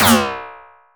Sci-Fi Weapons
sci-fi_weapon_blaster_laser_fun_04.wav